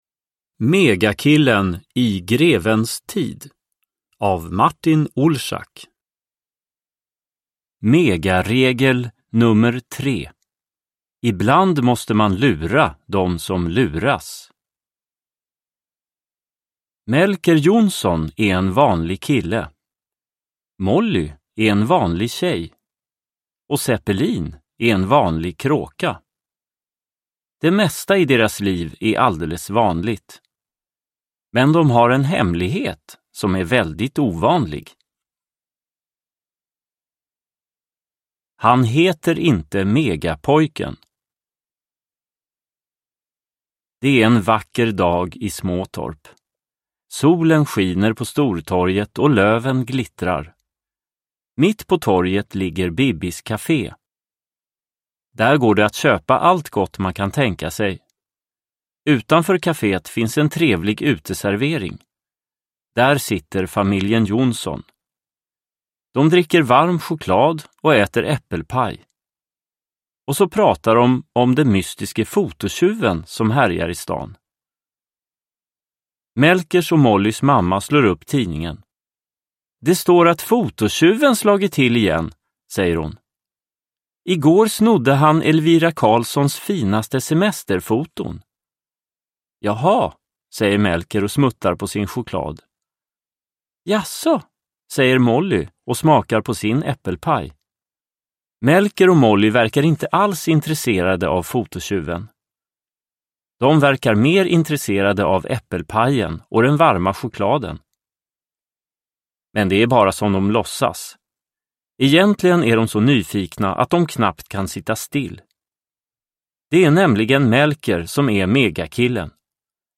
Megakillen i grevens tid – Ljudbok – Laddas ner